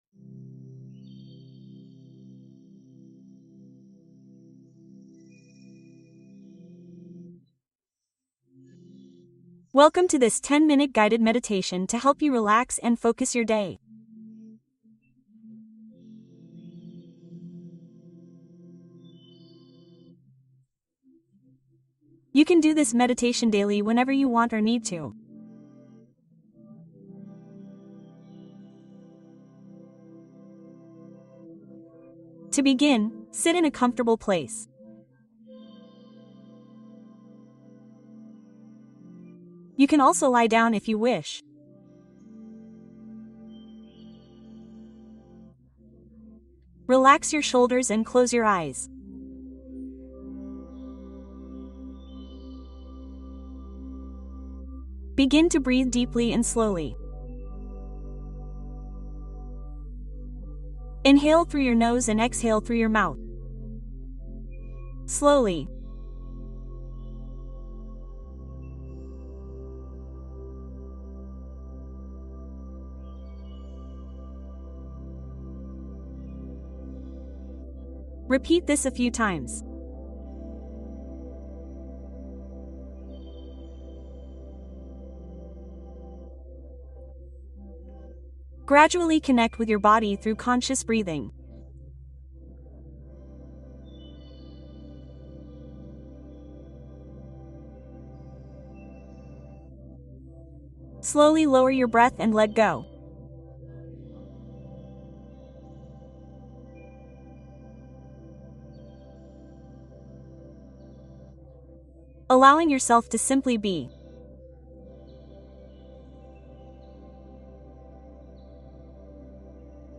Cuento para dormir Historia para descansar profundamente